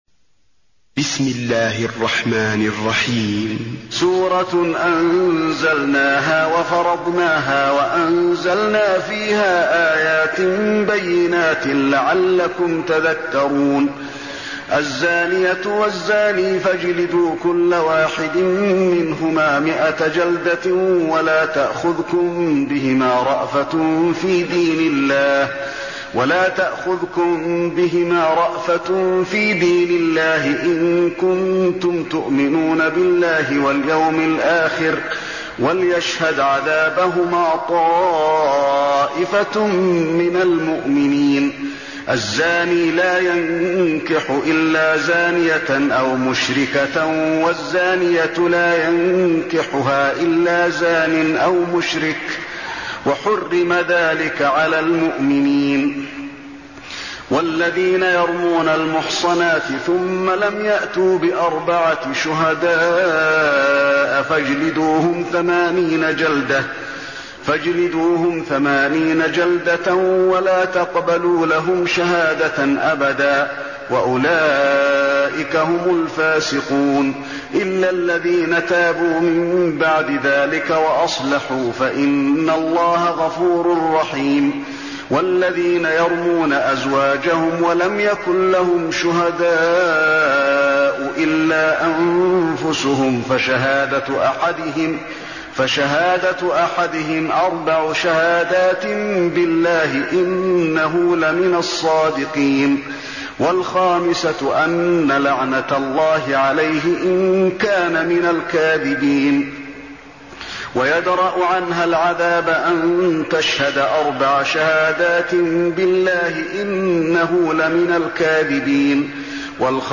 المكان: المسجد النبوي النور